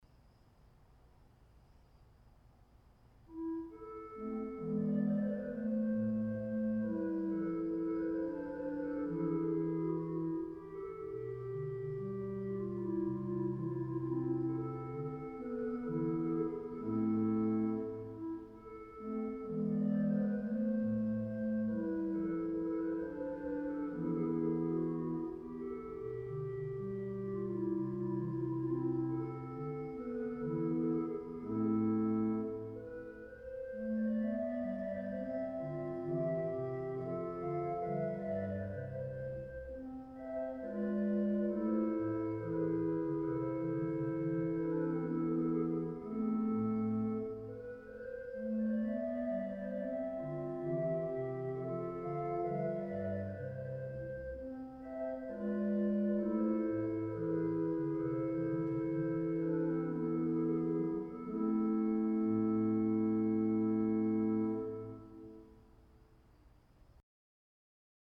Music for Violin and Organ